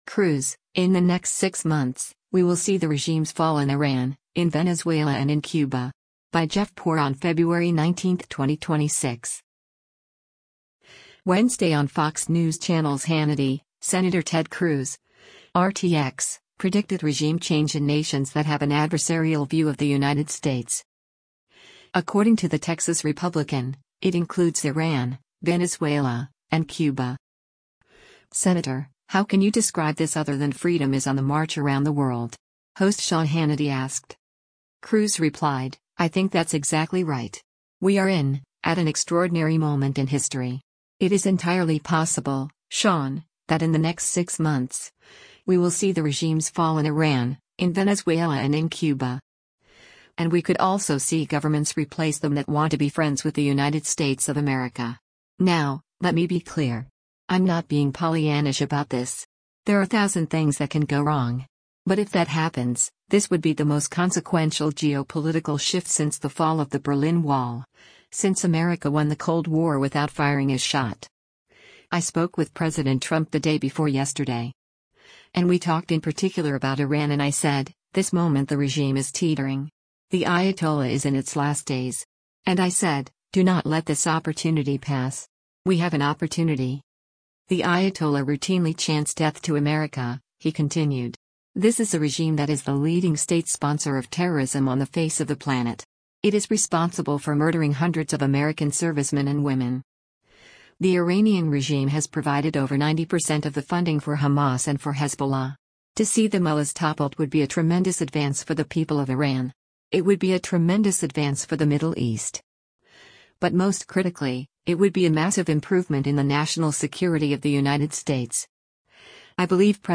Wednesday on Fox News Channel’s “Hannity,” Sen. Ted Cruz (R-TX) predicted regime change in nations that have an adversarial view of the United States.
“Senator, how can you describe this other than freedom is on the march around the world?” host Sean Hannity asked.